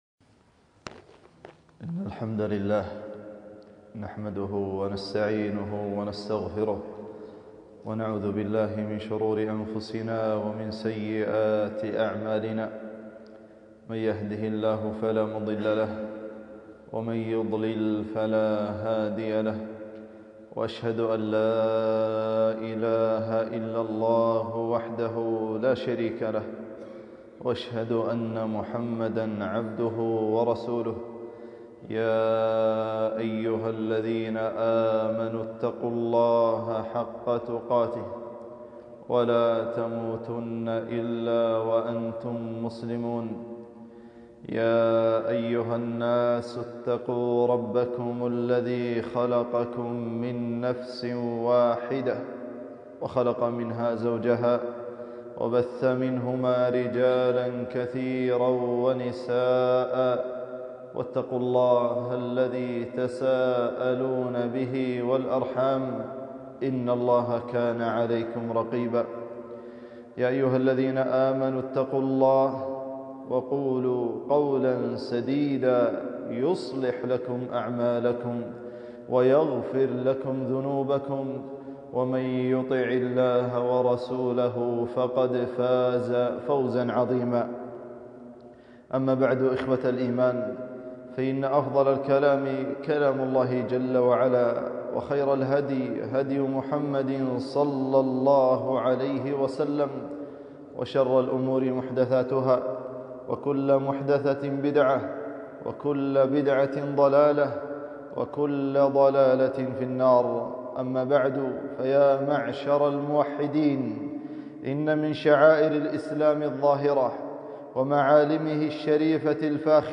خطبة - الأمر بالمعروف والنهي عن المنكر